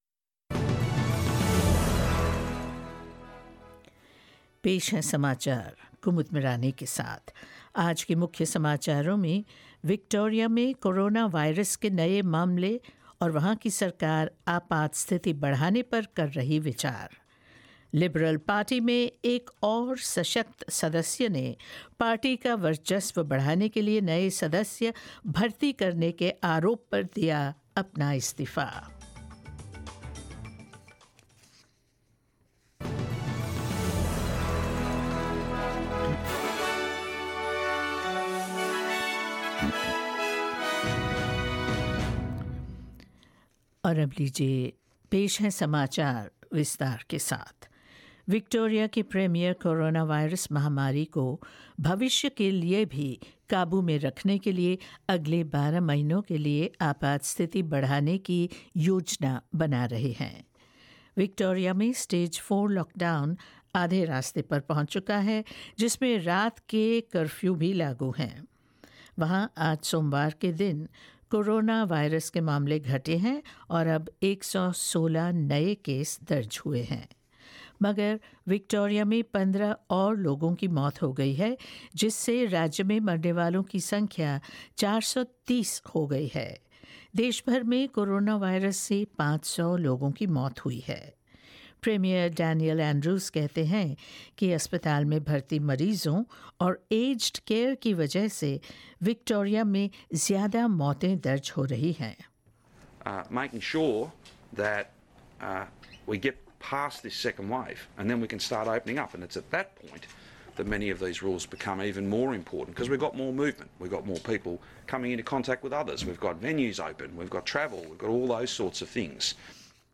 Hindi News 24th August 2020